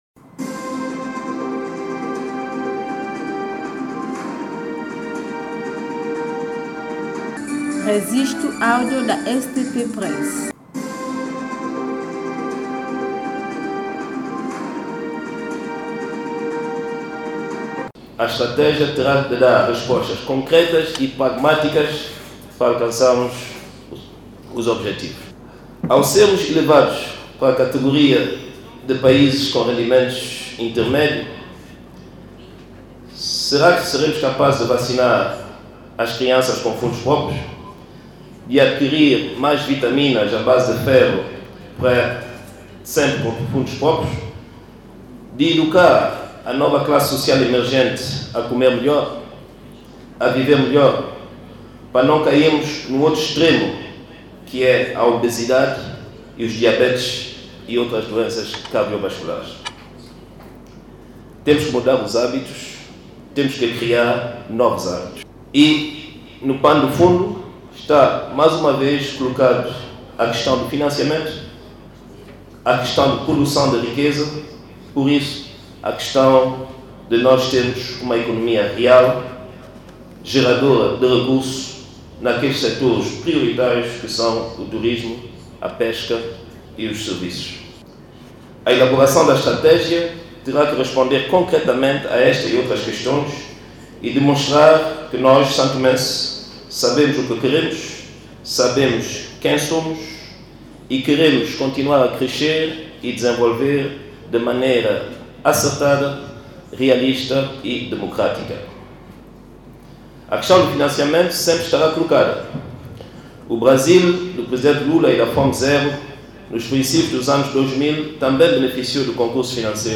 Trovoada fez estas declarações na cerimónia de lançamento oficial do “Relatório Final da Revisão Estratégica Fome Zero” 2030 esta manhã num dos hotéis de São-Tomé na presença da representante das Nações Unidas e outros parceiros de desenvolvimento bem como entidades públicas do País.
Declaração do Primeiro-Ministro, Patrice Trovoada